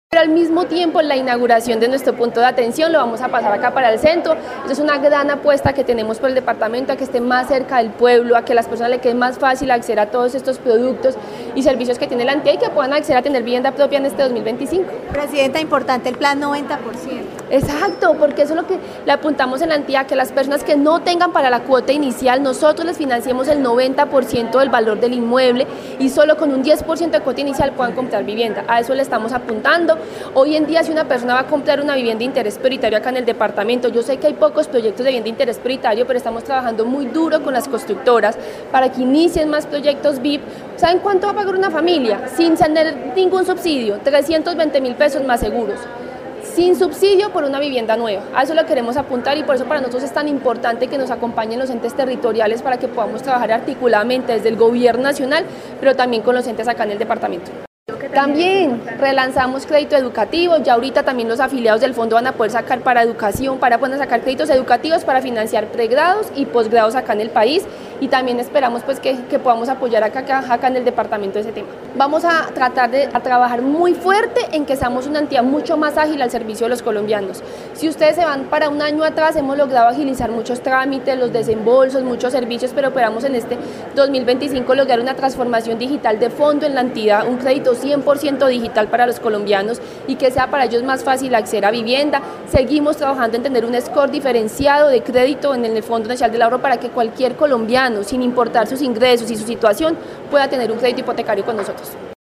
Presidenta del FNA
En el marco de un conversatorio que se llevó a cabo en las instalaciones de la Cámara de Comercio de Armenia, la presidenta del Fondo Nacional del Ahorro Laura Roa Zeidán dio a conocer los programas que están planteando con el fin de facilitar la compra de vivienda.